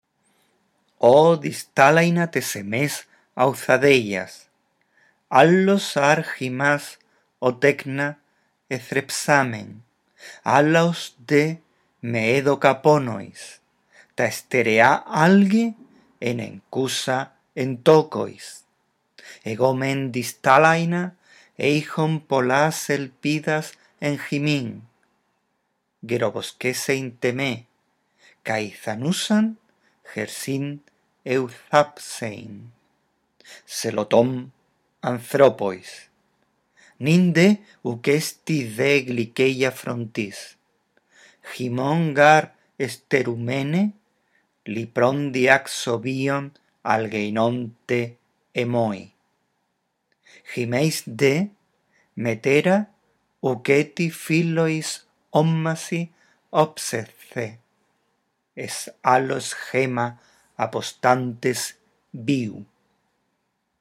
Lee el texto en voz alta, respetando los signos de puntuación.